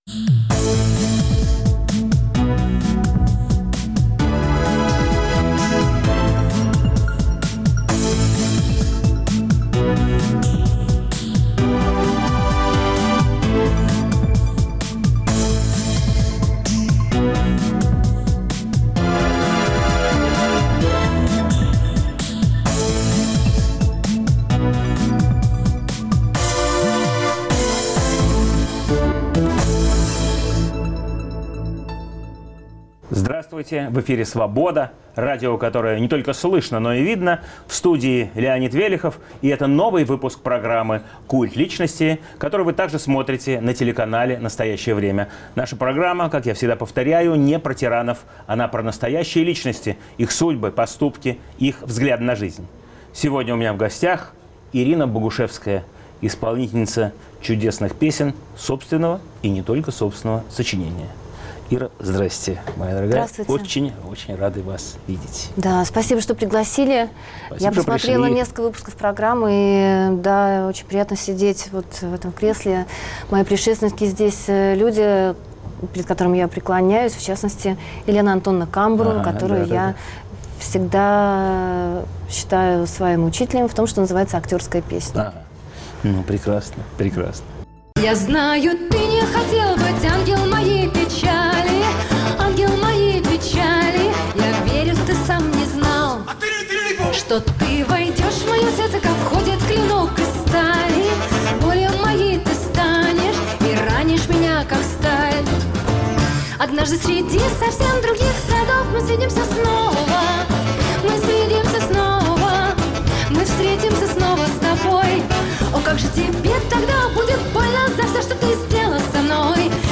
В студии "Культа личности" певица Ирина Богушевская .